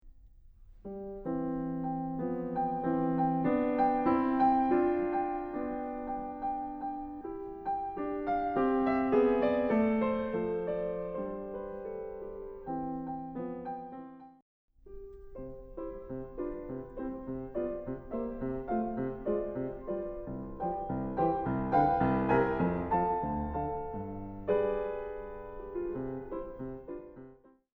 Momentum is building.
If you listen carefully, you will note that the melody went from being lovingly played in a lower voice in the first variation, to being more restless in the second variation.